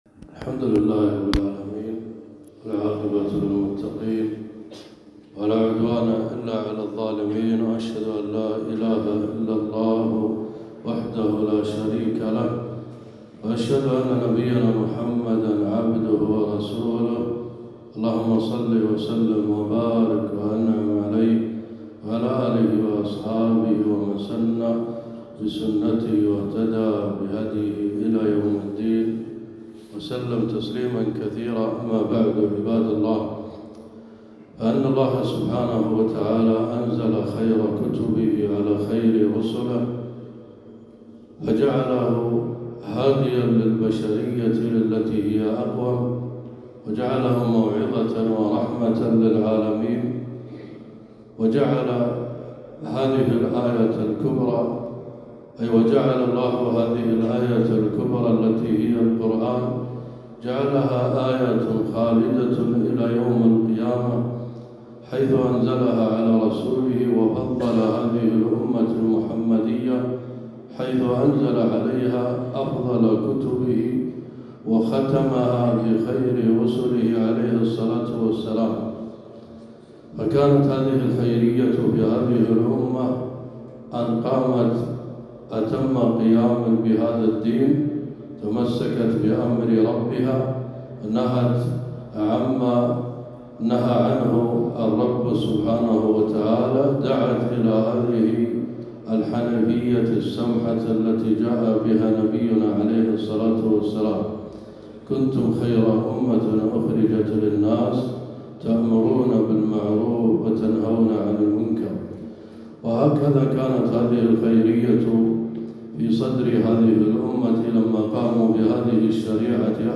خطبة - ( واسألهم عن القرية التي كانت حاضرة البحر )